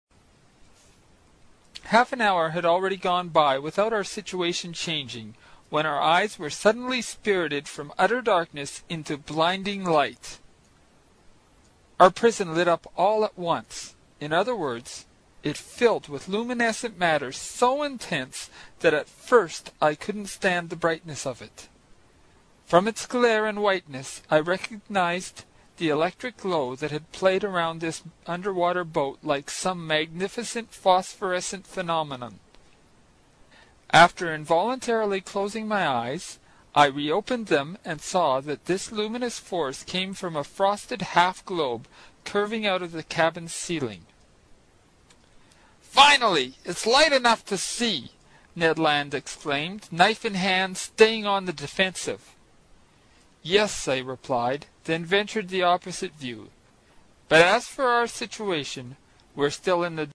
英语听书《海底两万里》第100期 第8章 动中之动(3) 听力文件下载—在线英语听力室
在线英语听力室英语听书《海底两万里》第100期 第8章 动中之动(3)的听力文件下载,《海底两万里》中英双语有声读物附MP3下载